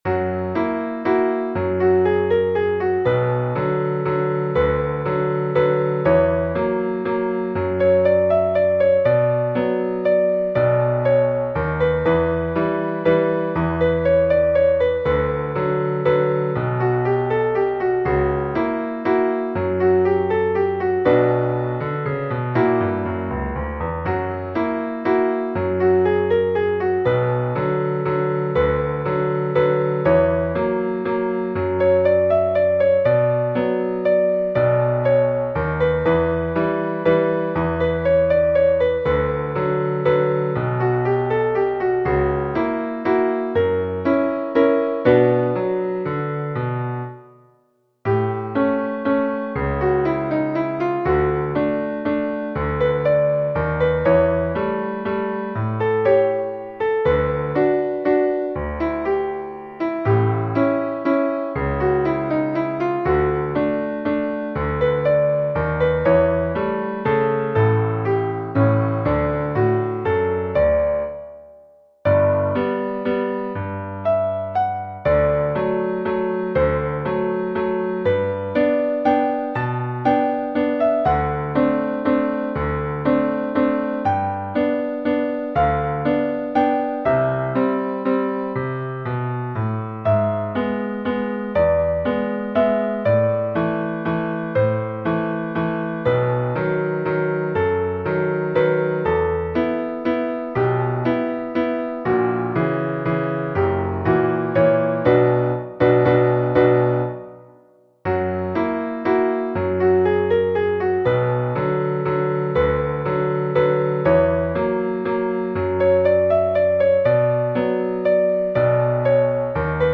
Versión: Arreglo para Piano Solo